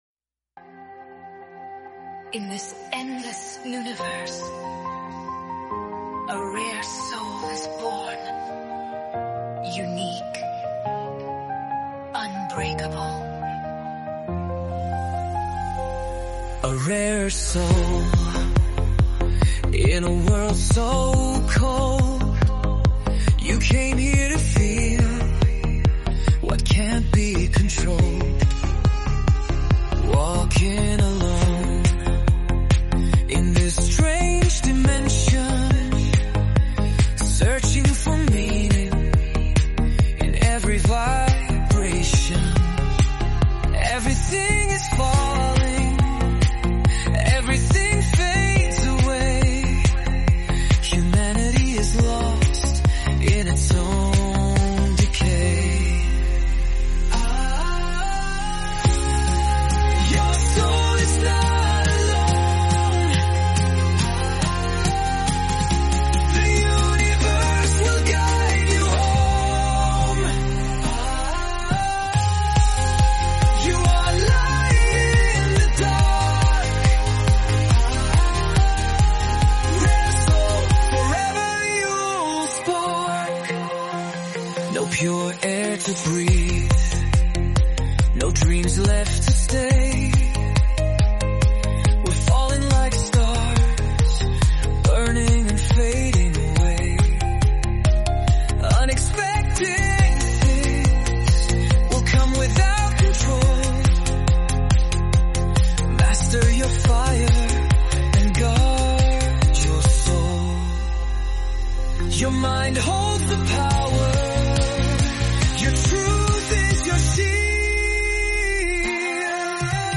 [Production DJ]